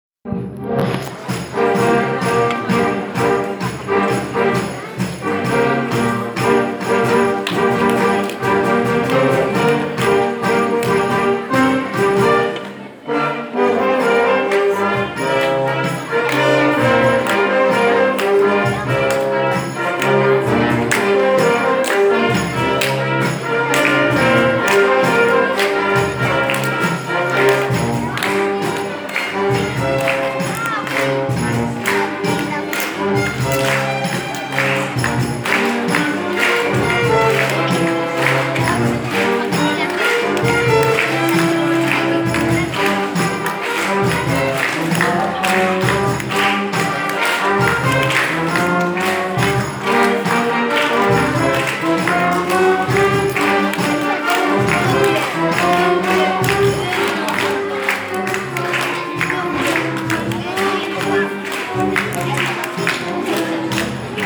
Classes orchestres : les concerts de fin d’année
Deux concerts ont été donnés, à l’occasion de la fête de la musique, à la salle des fêtes de Vandœuvre :
Classes élémentaires et collège
concert_college_1.mp3